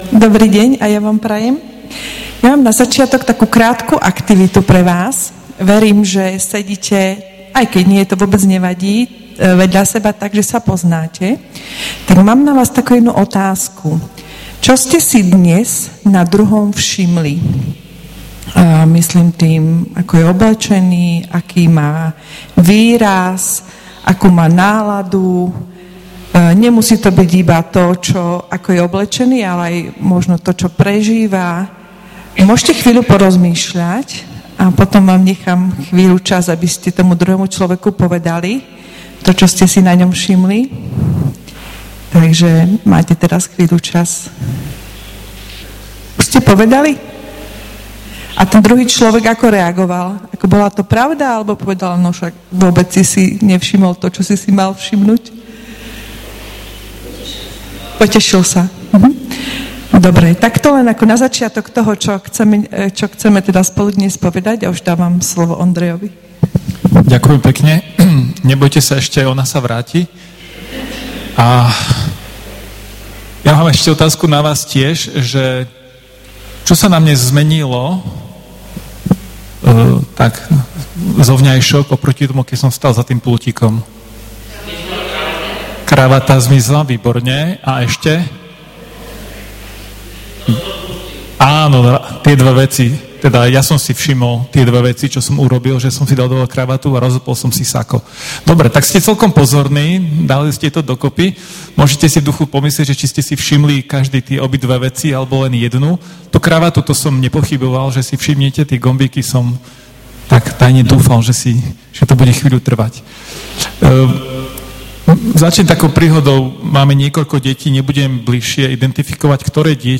V nasledovnom článku si môžete vypočuť zvukový záznam z Dňa rodiny.